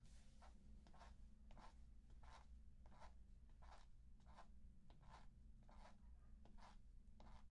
阶梯式室内软鞋底陪衬木质地板空心大房间6mx15mx6m第二部分
描述：步骤室内步行
标签： 步骤 室内 步行
声道立体声